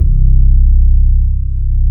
FRETLESSC2-L.wav